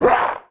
monster / goblin_leafhead / attack_1.wav
attack_1.wav